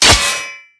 metal3.ogg